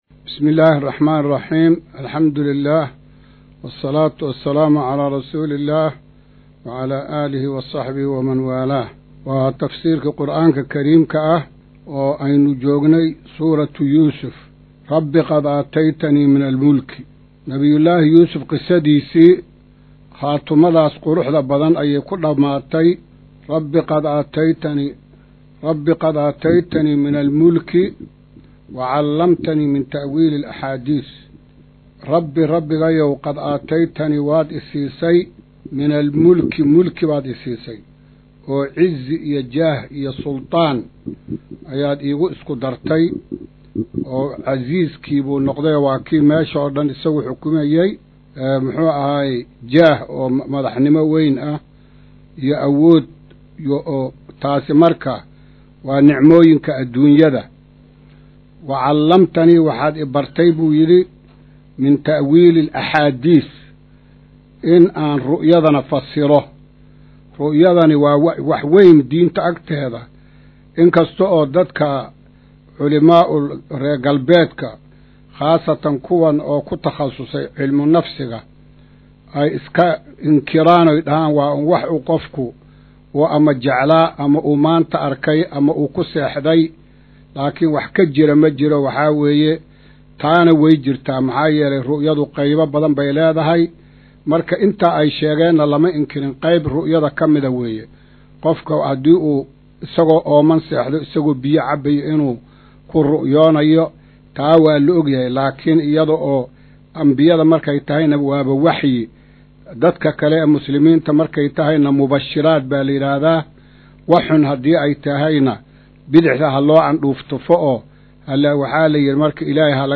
Maqal:- Casharka Tafsiirka Qur’aanka Idaacadda Himilo “Darsiga 122aad”